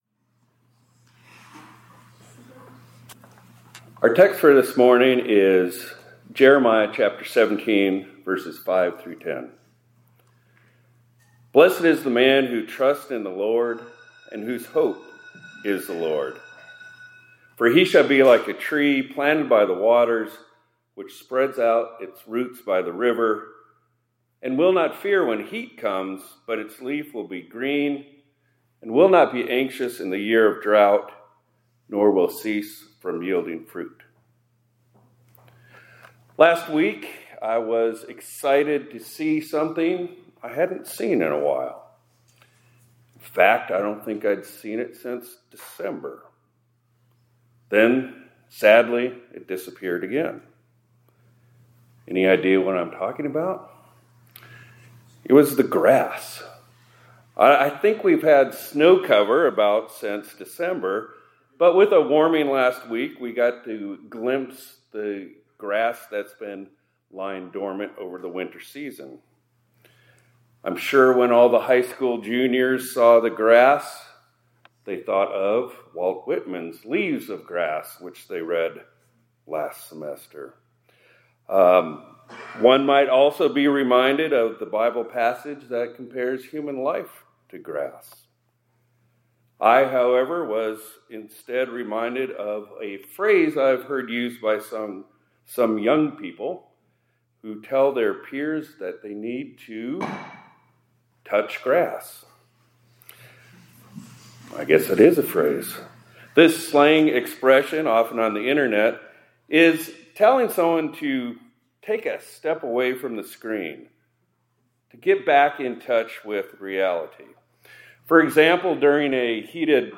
2026-02-26 ILC Chapel — During Lent, Touch Grass